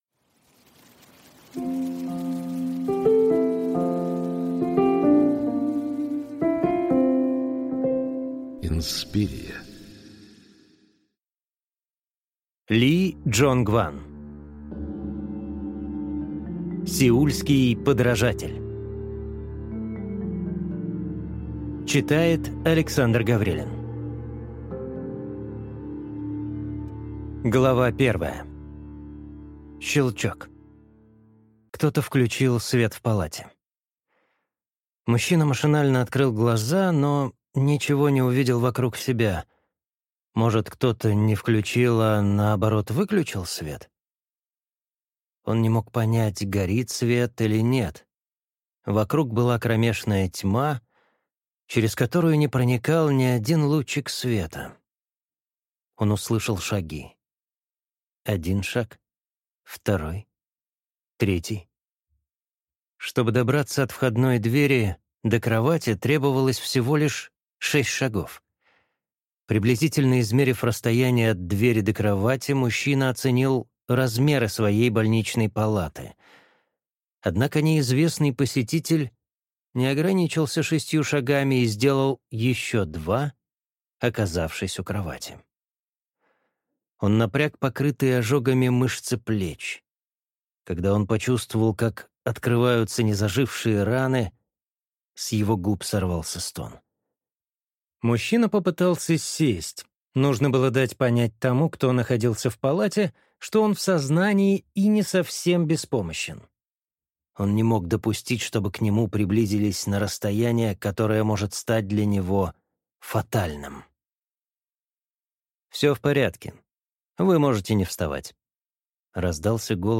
Орбита смерти (слушать аудиокнигу бесплатно) - автор Крис Хэдфилд